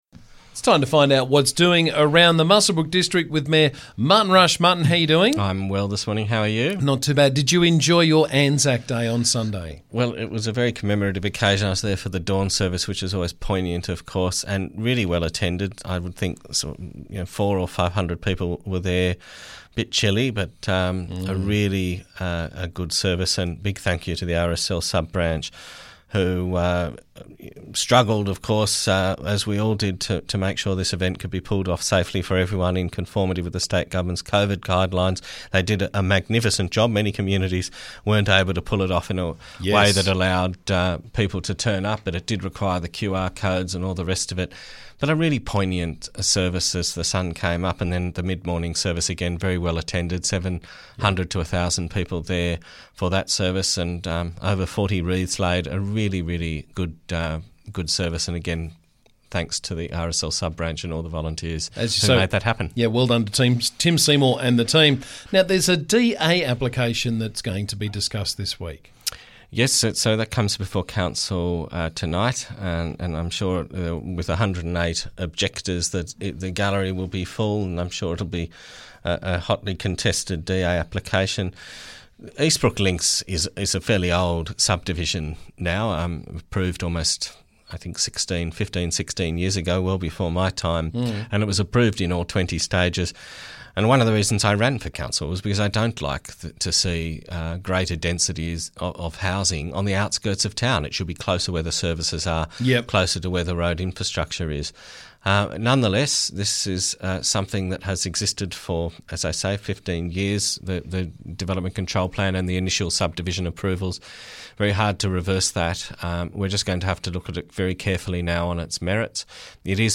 Muswellbrook Shire Council Mayor Martin Rush joined me to talk about the latest from around the district.